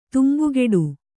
♪ tumbugeḍu